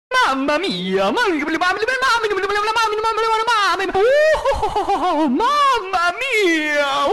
deltarune jackenstein mama mia Meme Sound Effect
Category: Games Soundboard